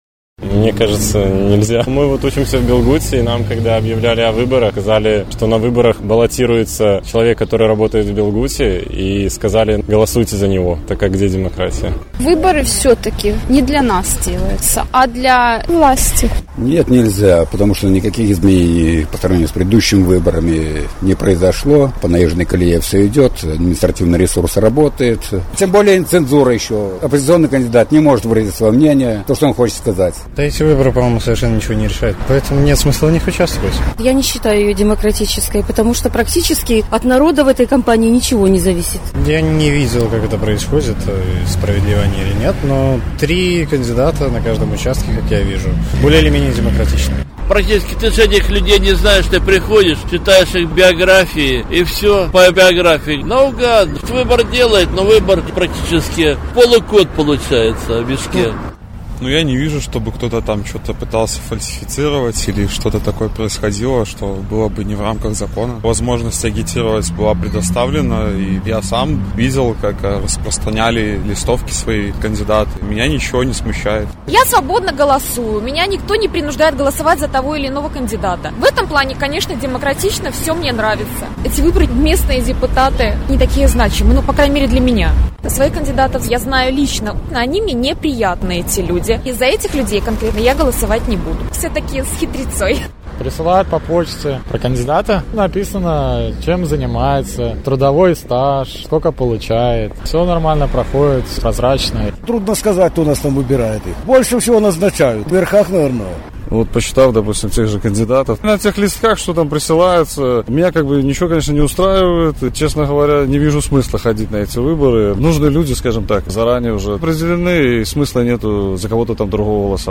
Апытанка ў Гомелі
На гэтае пытаньне адказваюць жыхары Гомеля.